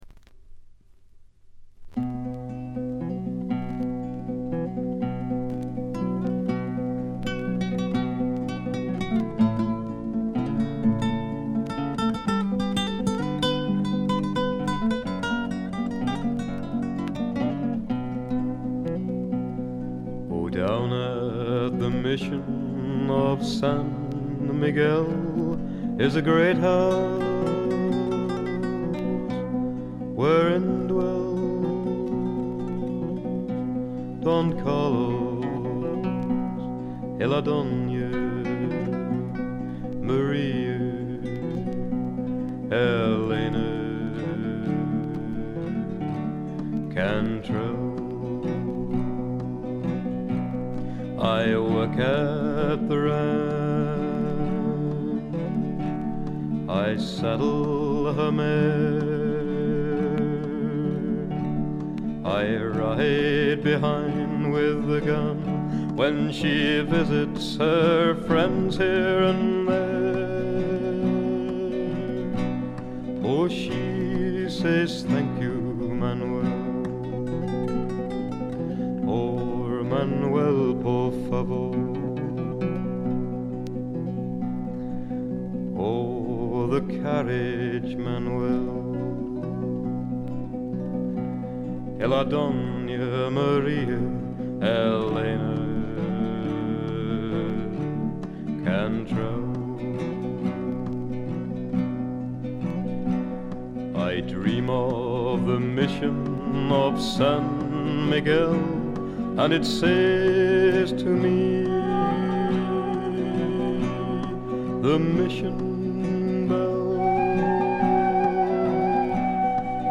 静音部でチリプチ少々。ところどころで散発的なプツ音。
試聴曲は現品からの取り込み音源です。
Guitar, Lead Vocals
Guitar, Banjo, Mandolin, Vocals
Flute, Vocals